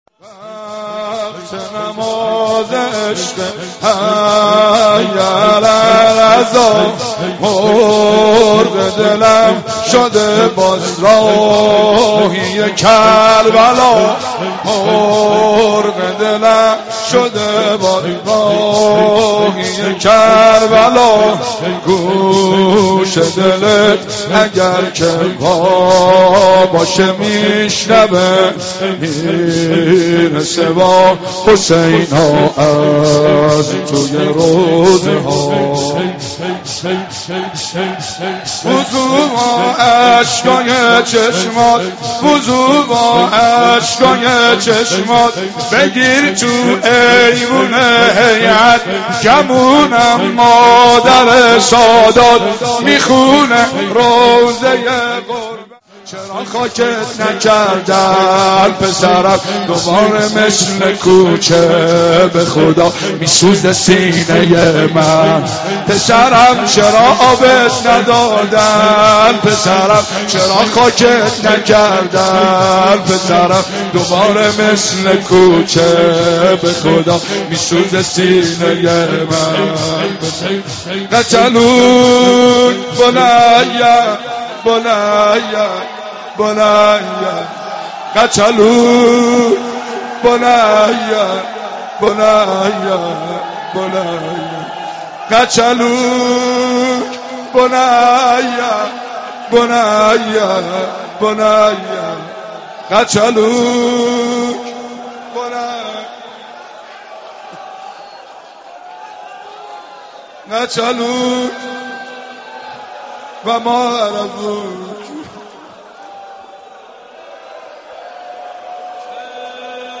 شب اول محرم